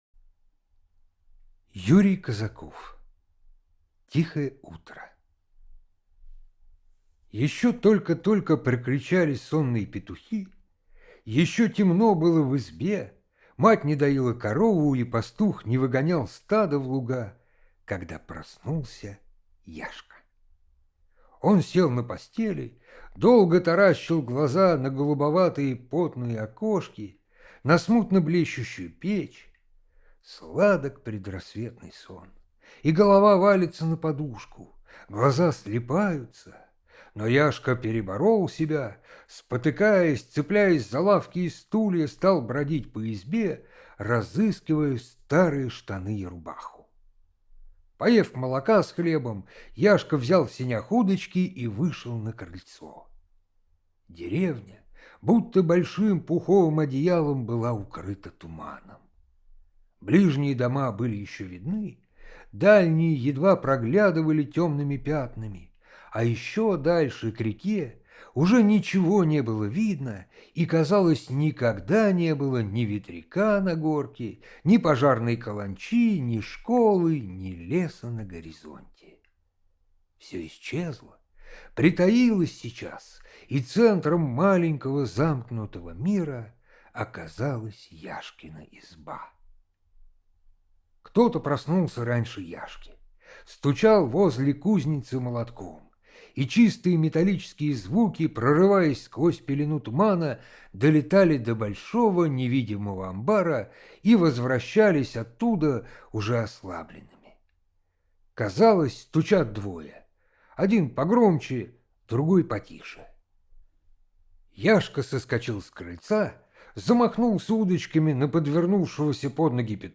Тихое утро - аудио рассказ Казакова - слушать онлайн